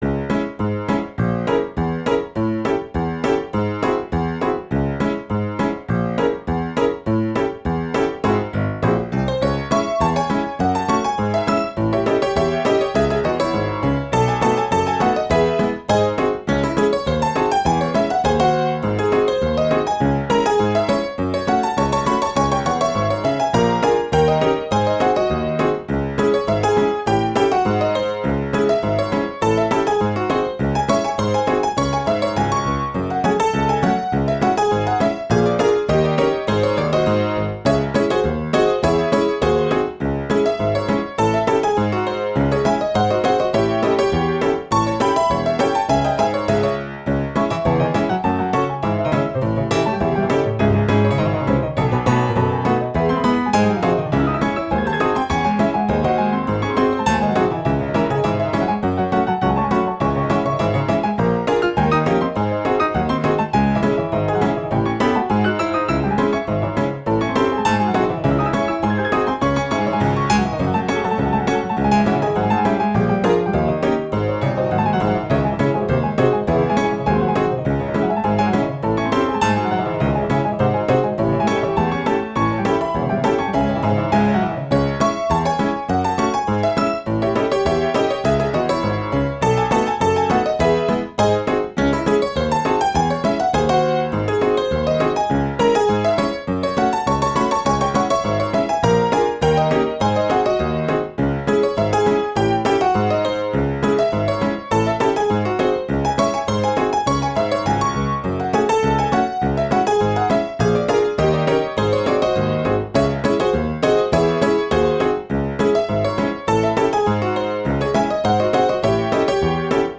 Detune (Ragtime)
この曲はいつもダンスミュージックばかりで気が滅入った時にリフレッシュのために作った曲です。
短編ではありますが物語があり、ピエロが黙々と寂しく一人で晩御飯の支度をしているという感じの風景を思いながら書いた曲です。